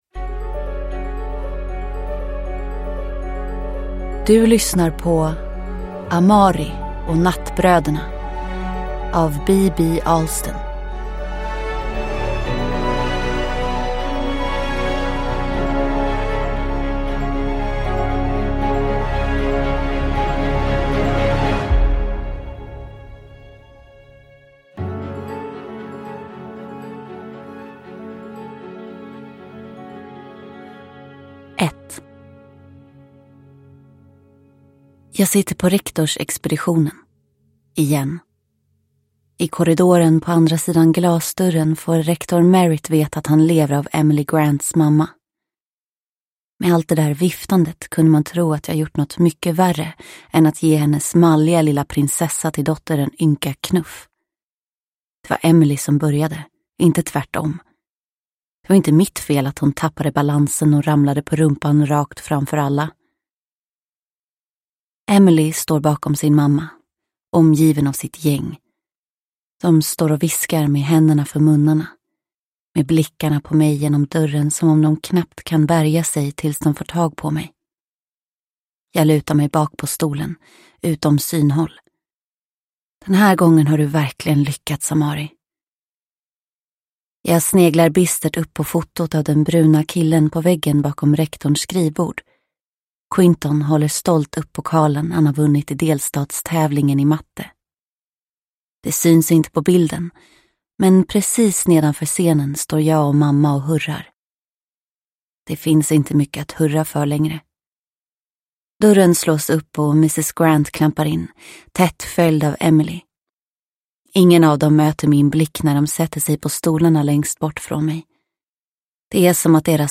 Amari och Nattbröderna – Ljudbok – Laddas ner